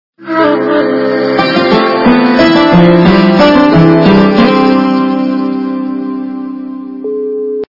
» Звуки » звуки для СМС » Для SMS